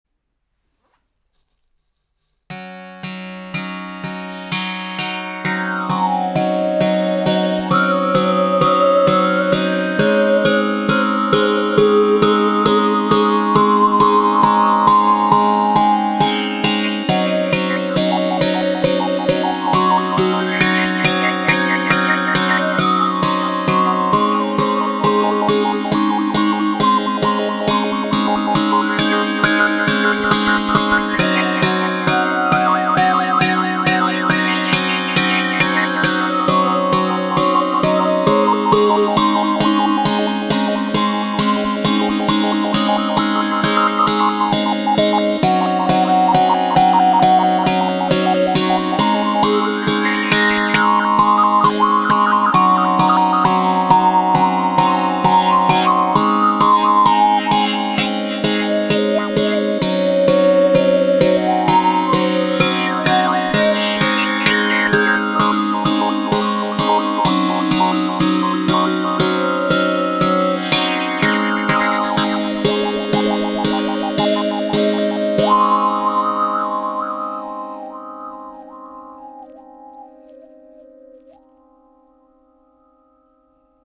COSMICBOW 5 CORDES avec cuillère harmonique
Le son est très chaud et attrayant…entrainant.
Les sons proposés ici sont réalisés sans effet.